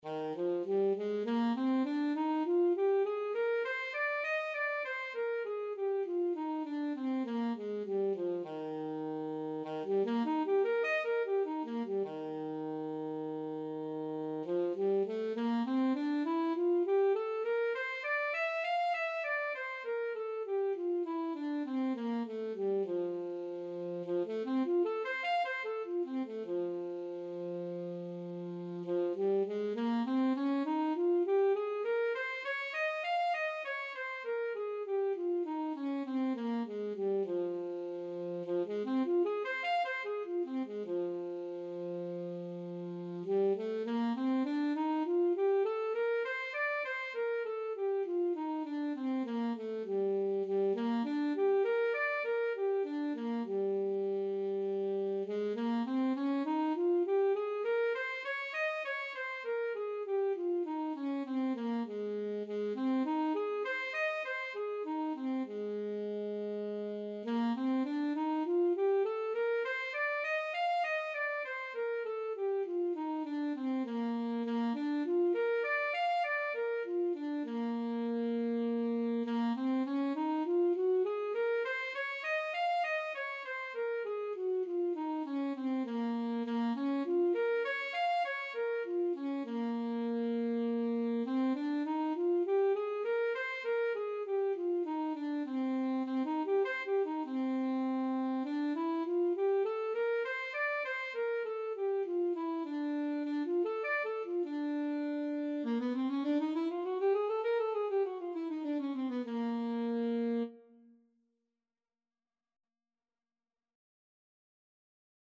Classical Saxophone scales and arpeggios - Grade 3 Alto Saxophone version
Eb major (Sounding Pitch) C major (Alto Saxophone in Eb) (View more Eb major Music for Saxophone )
4/4 (View more 4/4 Music)
Eb4-F6
saxophone_scales_grade3_ASAX.mp3